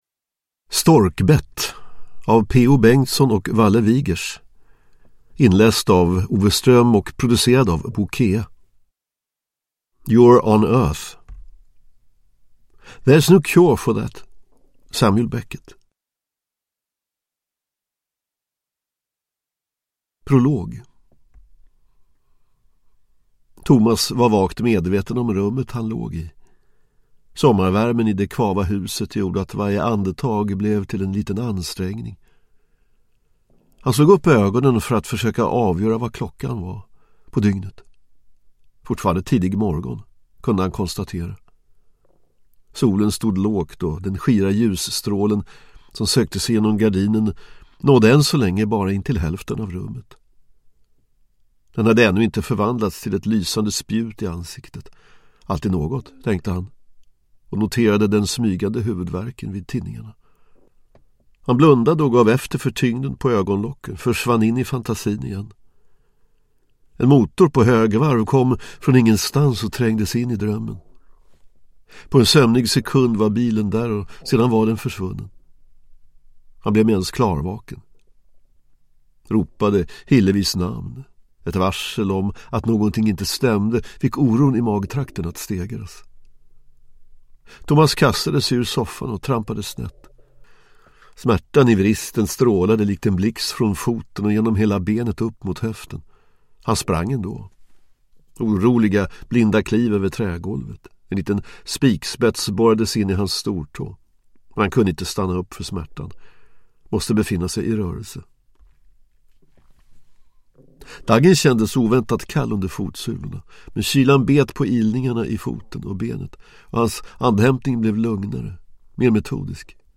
Storkbett (ljudbok) av Peo Bengtsson | Bokon